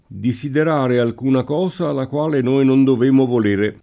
diSider#re alk2na k0Sa la kU#le noi non dov%mo vol%re] (Brunetto); Cosa disiderata assai più giova [k0Sa diSider#ta aSS#i pLu JJ1va] (Poliziano)